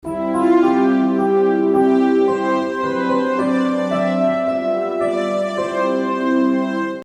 Keyboard
keyboard Tasteninstrument
Das Keyboard ist ein elektronisches Tasteninstrument, das sowohl Solo als auch als Bandinstrument eingesetzt werden kann. Es gibt keinen eigentlichen „Keyboard-Klang“: Der Klang kann  je nach Einstellung variiert werden – manchmal klingt es wie ein Piano, dann wie eine Flöte oder gar wie ein Chor.
Keyboard.mp3